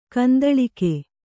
♪ kandaḷike